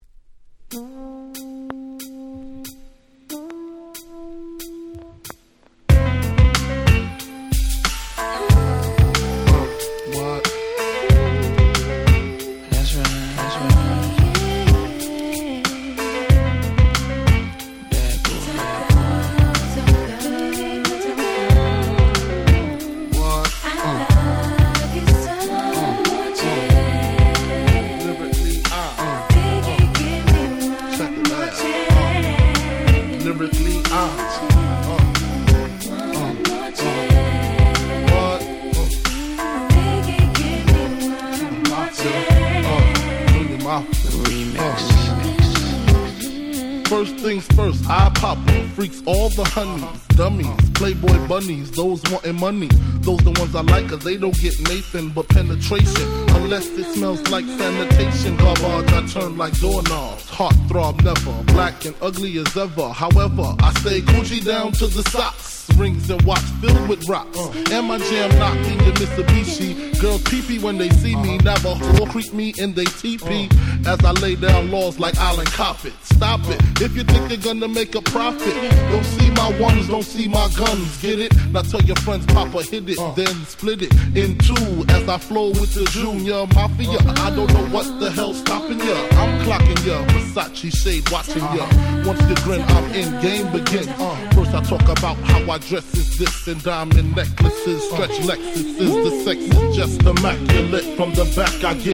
90's Hip Hop Super Classics !!